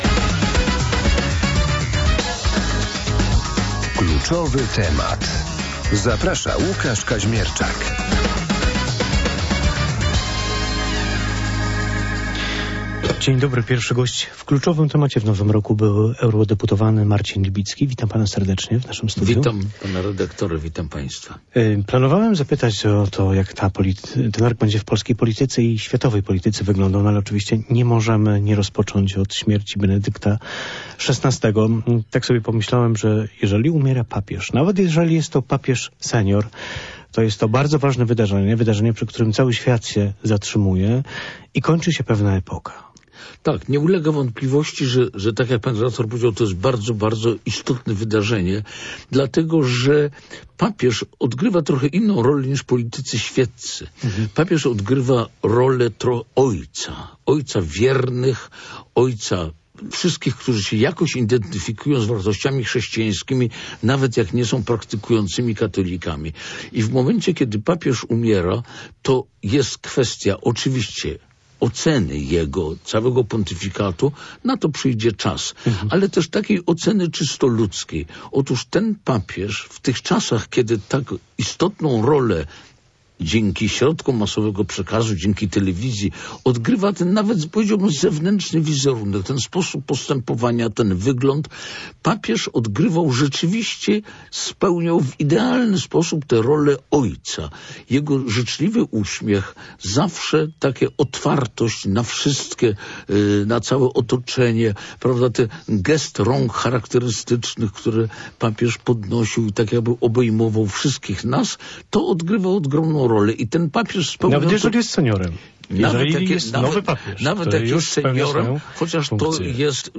Marcin Libicki był gościem porannej rozmowy Radia Poznań. Były polityk podkreśla, że najbardziej prawdopodobnym scenariuszem wyborów parlamentarnych będzie zwycięstwo Prawa i Sprawiedliwości.